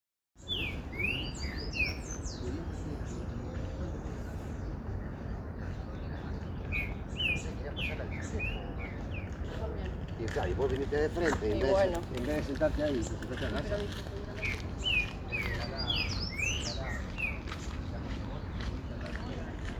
Green-winged Saltator (Saltator similis)
Location or protected area: Reserva Ecológica Costanera Sur (RECS)
Condition: Wild
Certainty: Observed, Recorded vocal
PEPITERO-VERDOSO.mp3